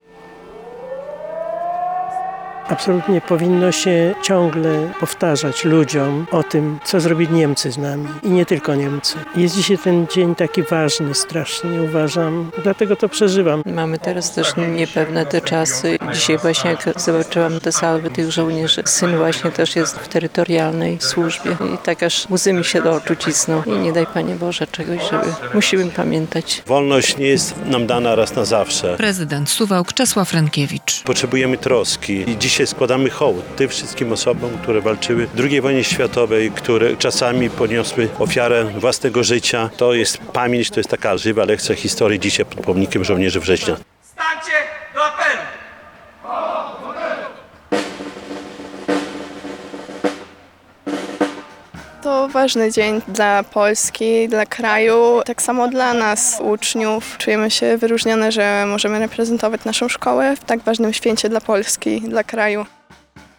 Salwą honorową i apelem poległych mieszkańcy Suwałk uczcili 86. rocznicę wybuchu II Wojny Światowej.